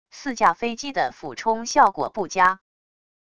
四架飞机的俯冲效果不佳wav音频